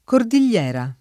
vai all'elenco alfabetico delle voci ingrandisci il carattere 100% rimpicciolisci il carattere stampa invia tramite posta elettronica codividi su Facebook cordillera [sp. kor D il’ % ra ] s. f.; pl. cordilleras [ kor D il’ % ra S ] — freq. in toponimi ispano‑americani («catena») — italianizz. in cordigliera [ kordil’l’ $ ra ]